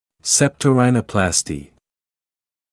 [ˌseptəuˈraɪnə(u)ˌplɑːstɪ][ˌсэптоуˈрайно(у)ˌплаːсти]риносептопластика